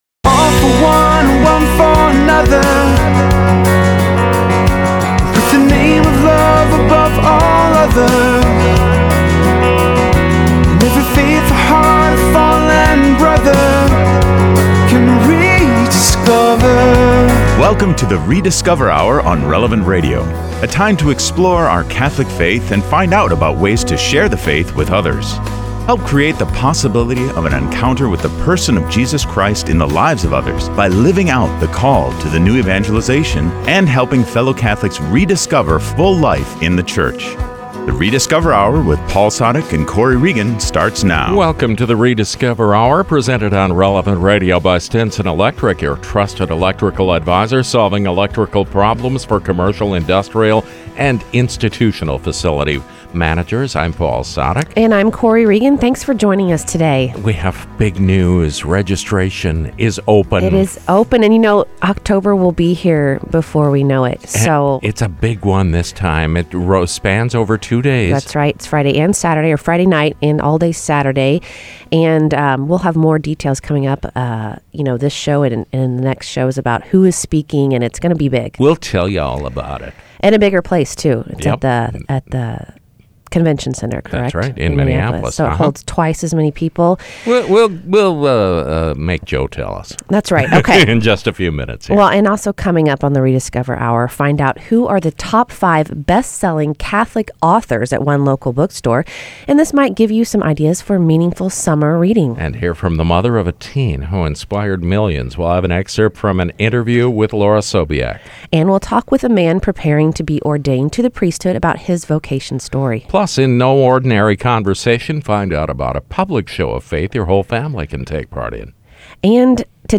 Plus, in No Ordinary Conversation, find out about a public show of faith your whole family can take part in. And we talk with a man preparing to be ordained to the priesthood about his vocation story.